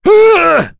Kain-Vox_Damage_05.wav